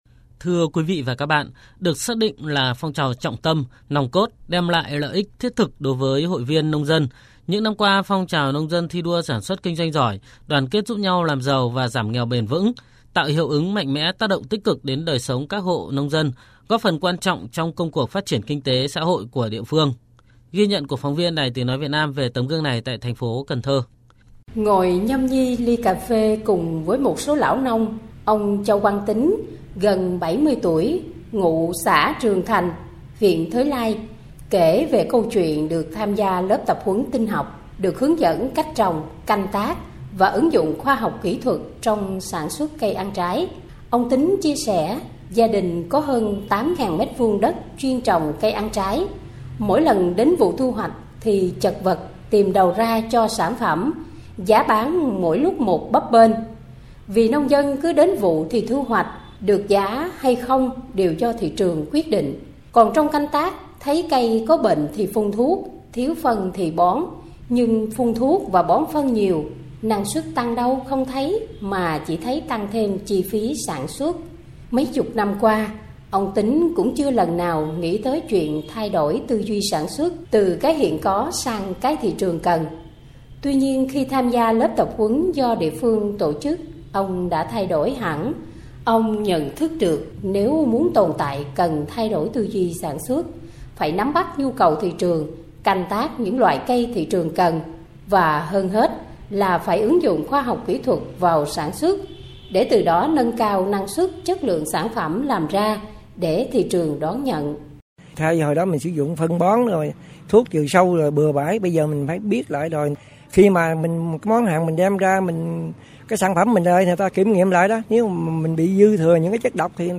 30 Phóng sự giảm nghèo